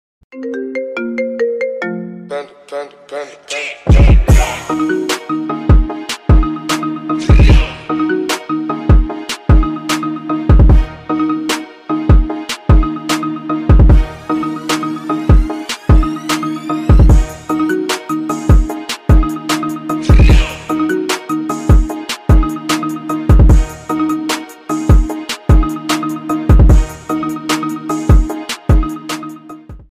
SFX抖音最火iPhone铃声音效下载
SFX音效